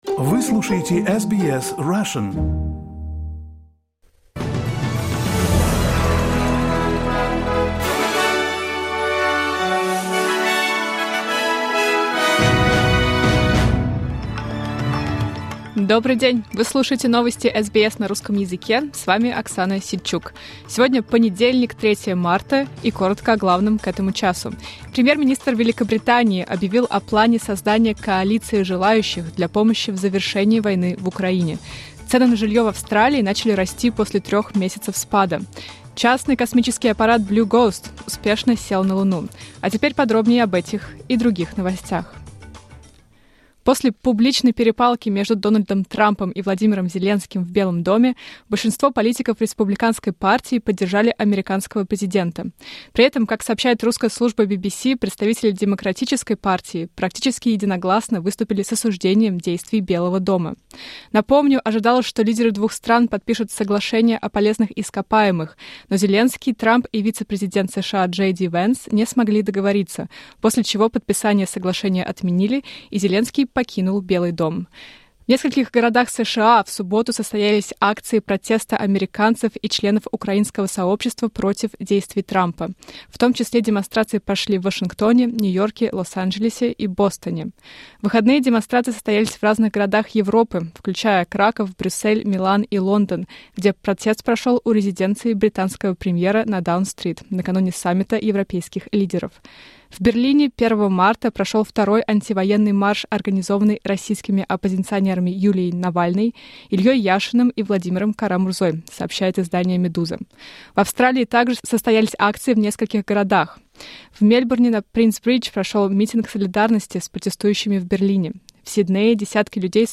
Новости SBS на русском языке — 03.03.2025